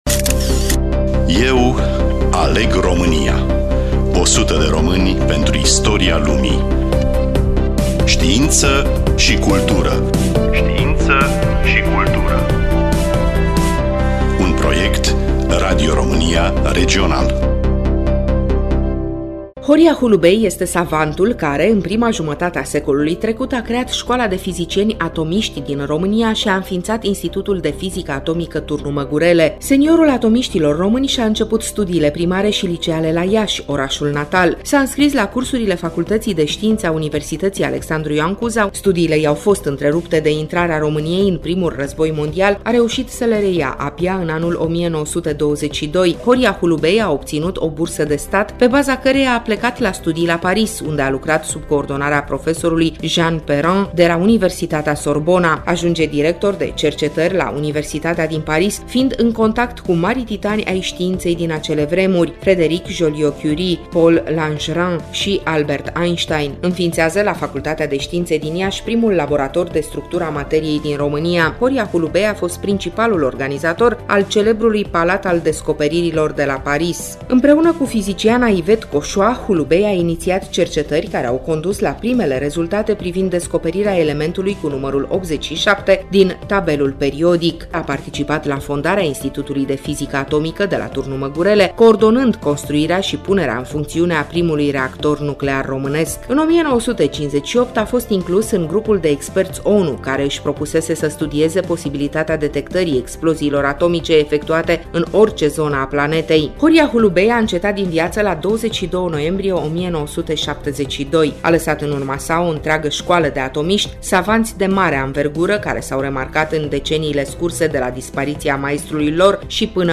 Studioul: Radio Romania Oltenia-Craiova